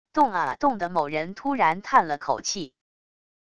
动啊动的某人突然叹了口气wav音频